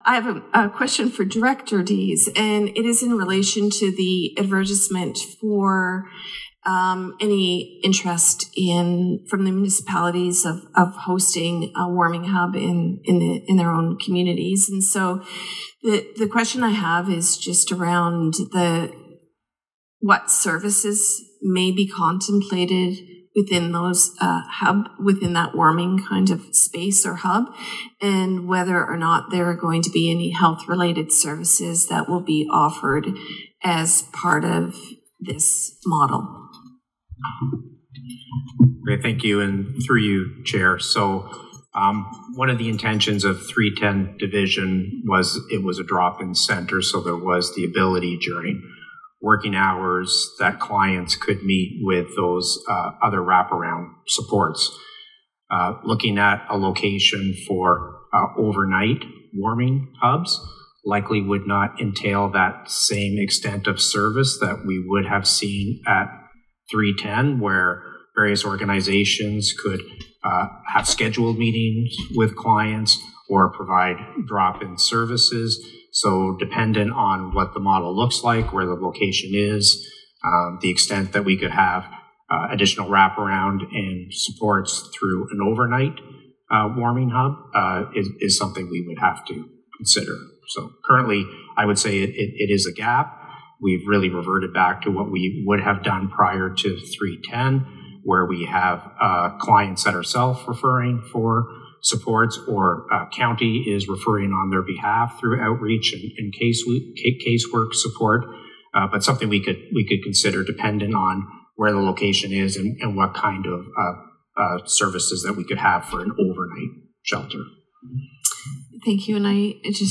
Cobourg Mayor Lucas Cleveland asked Cramahe Township Mayor Mandy Martin why her municipality was not stepping up, while Martin defended their efforts.
Listen to the committee members debate from July 29: